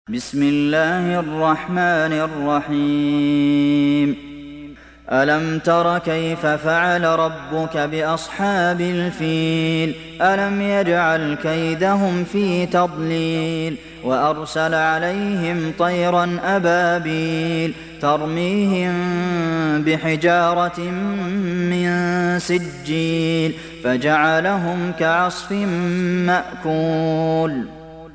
دانلود سوره الفيل mp3 عبد المحسن القاسم روایت حفص از عاصم, قرآن را دانلود کنید و گوش کن mp3 ، لینک مستقیم کامل